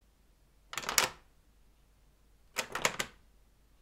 دانلود صدای قفل در با کلید از ساعد نیوز با لینک مستقیم و کیفیت بالا
جلوه های صوتی
برچسب: دانلود آهنگ های افکت صوتی اشیاء دانلود آلبوم صدای قفل کردن در از افکت صوتی اشیاء